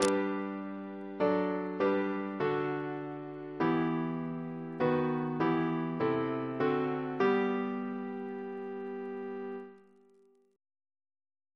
Single chant in G Composer: William Taylor (b.1878) Reference psalters: PP/SNCB: 2